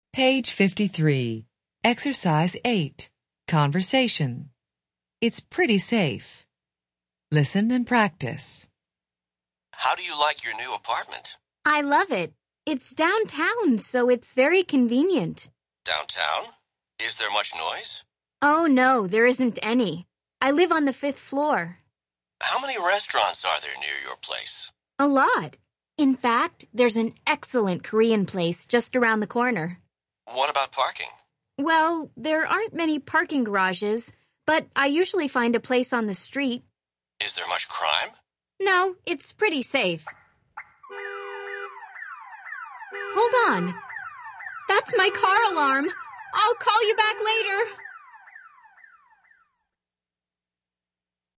American English
Interchange Third Edition Level 1 Unit 8 Ex 8 Conversation Track 24 Students Book Student Arcade Self Study Audio